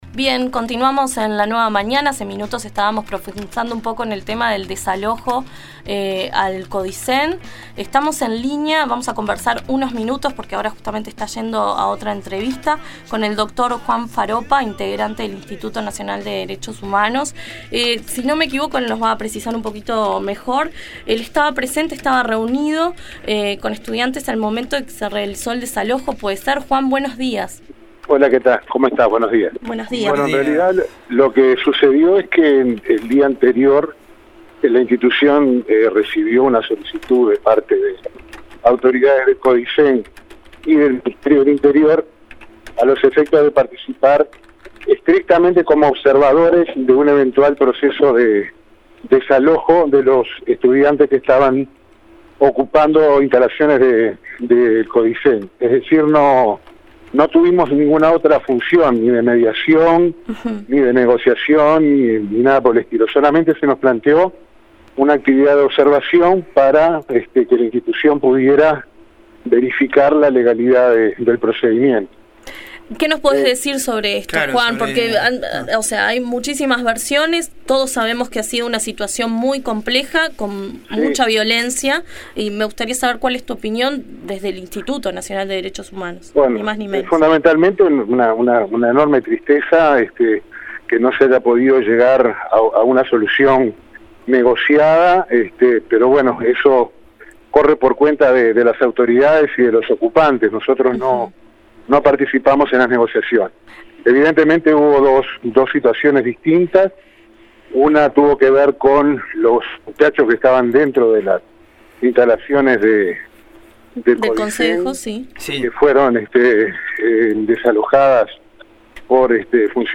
En La Nueva Mañana conversamos con el Dr. Juan Faroppa, integrante del Institución Nacional de Derechos Humanos (INDDHH), quien estuvo presente al momento del desalojo de los estudiantes de secundaria en el edificio del Codicen.